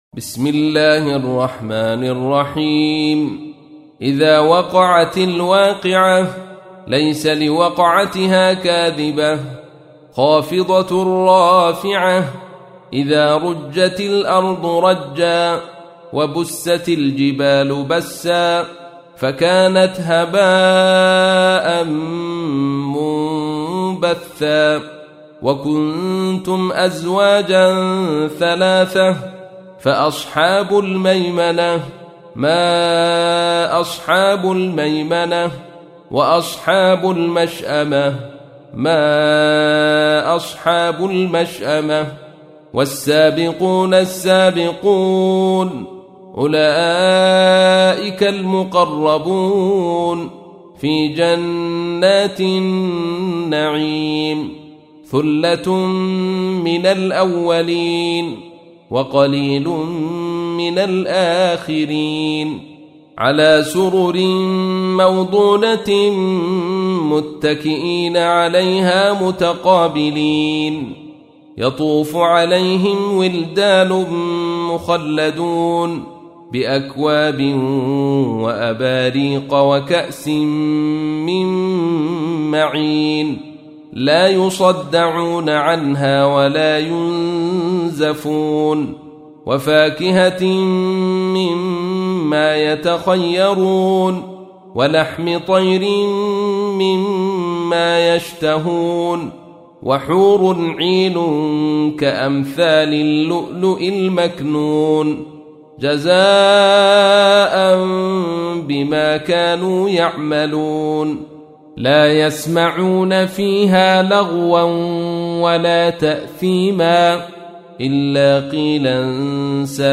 تحميل : 56. سورة الواقعة / القارئ عبد الرشيد صوفي / القرآن الكريم / موقع يا حسين